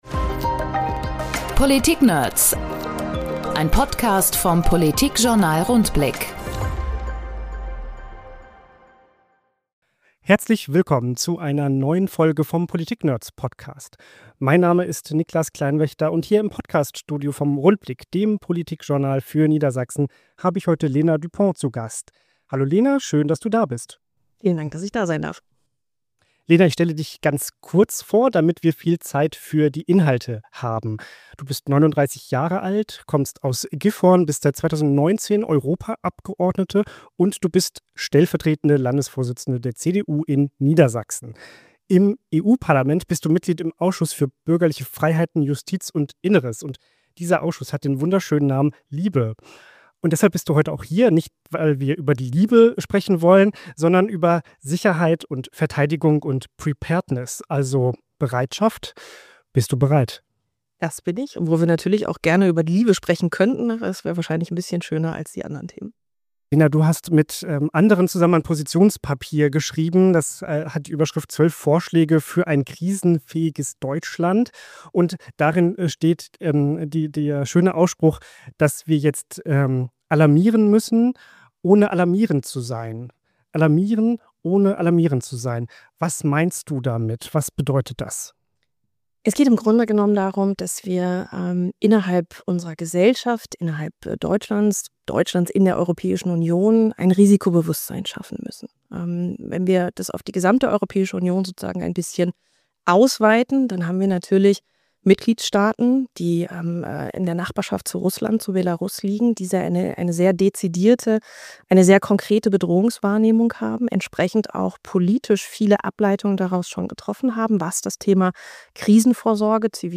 Podcast-Gespräch